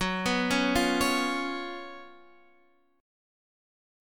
Listen to GbM7sus4 strummed